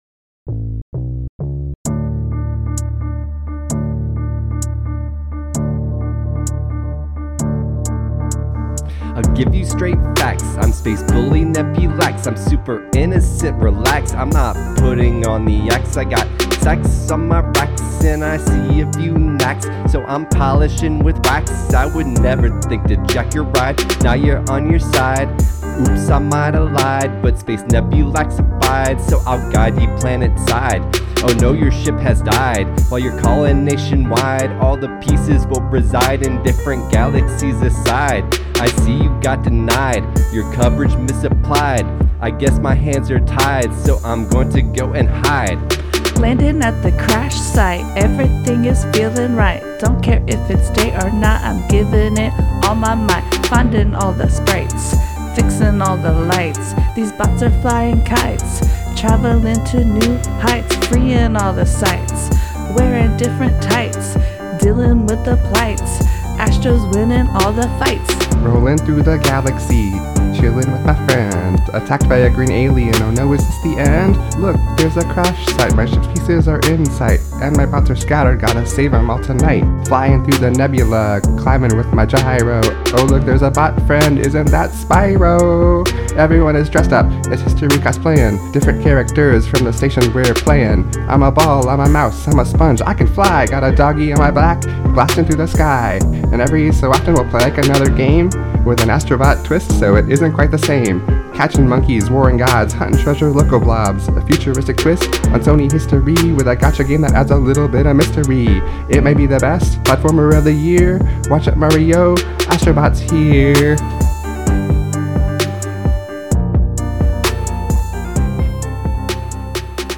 Rap from Episode 92: Astro Bot – Press any Button
ep92-astro-bot-rap-ii.mp3